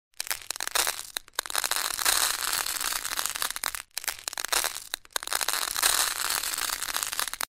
Tiếng Rắc muối, gia vị bằng Tay
Thể loại: Tiếng ăn uống
Âm thanh “lách tách”, “rào rào” khi những hạt muối, đường, tiêu hay bột gia vị rơi từ kẽ tay xuống món ăn, vang lên nhẹ nhàng nhưng rõ rệt. Âm thanh gần gũi, gợi cảm giác thủ công, tinh tế trong quá trình chế biến, thường xuất hiện trong cảnh nấu ăn hoặc trình diễn ẩm thực.
tieng-rac-muoi-gia-vi-bang-tay-www_tiengdong_comtieng-rac-muoi-gia-vi-bang-tay-www_tiengdong_com.mp3